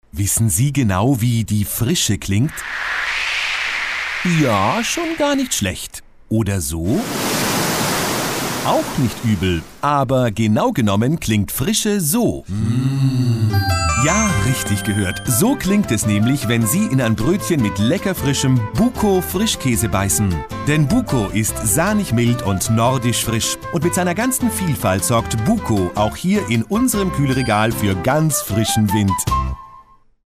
German Male Voice-Over Artist
German-Mvo-2-1.mp3